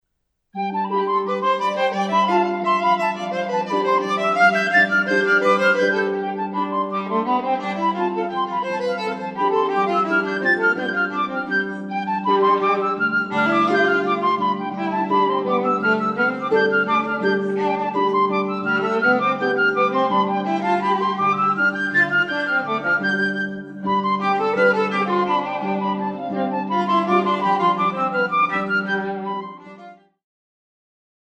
flute, harp and viola recording - American music
flute
harp
Flute, Viola, Harp CD sacred music